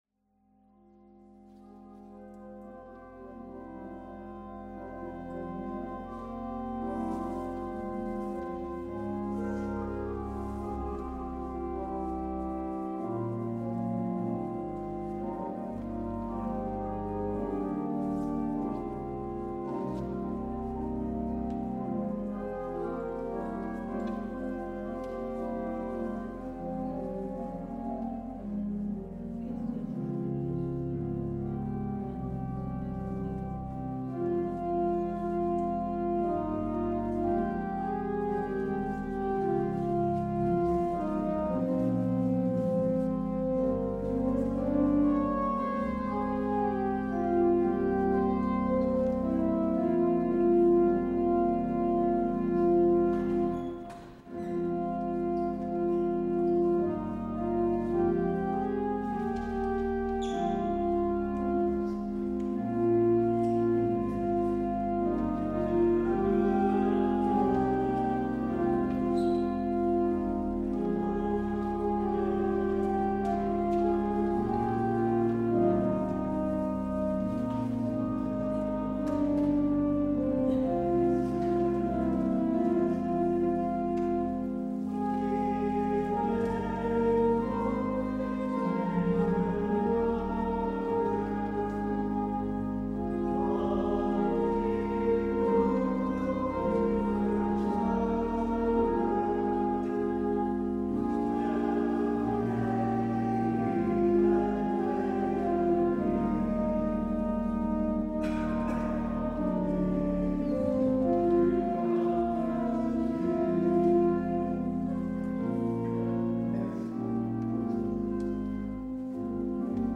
Beluister deze kerkdienst hier: